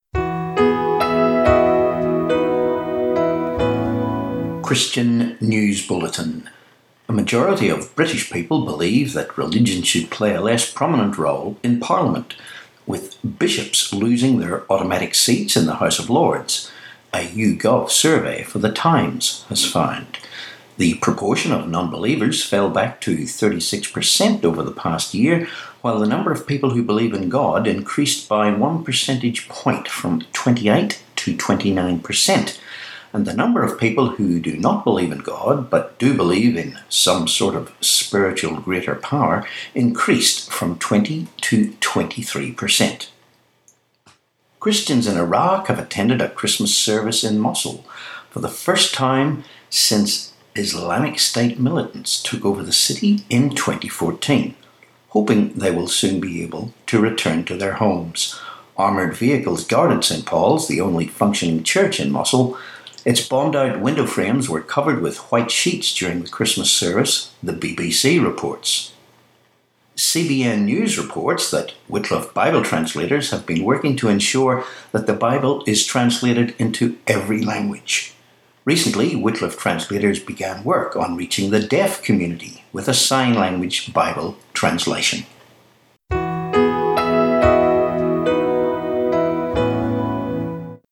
31Dec17 Christian News Bulletin